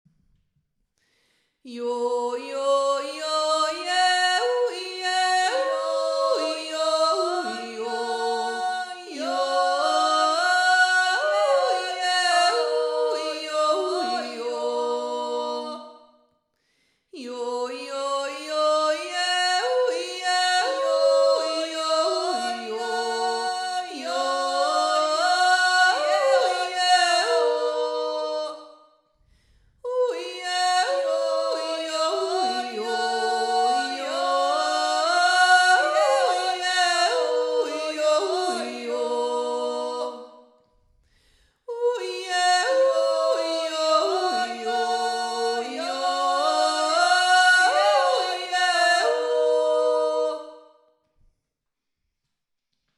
Die Jodler
2. Stimme